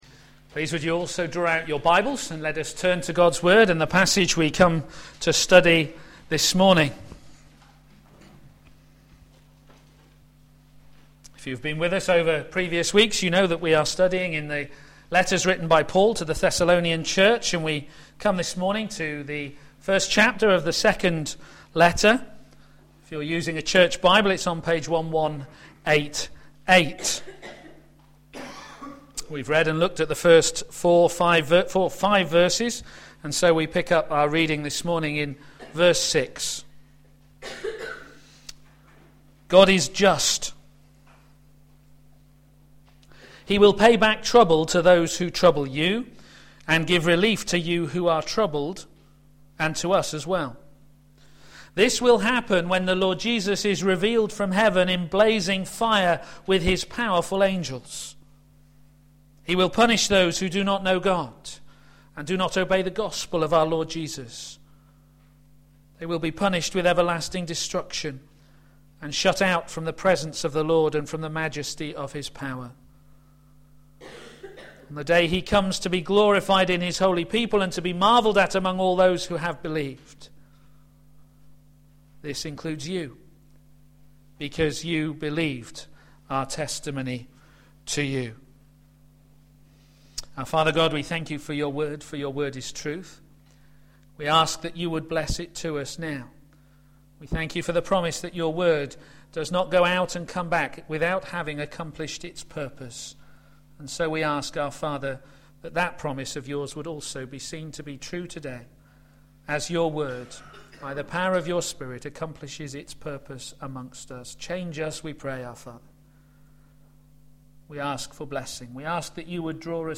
a.m. Service on Sun 06th Mar 2011 10:30
Series: A Church to be Proud of and Thankful for Theme: Hoping for the Right Future Sermon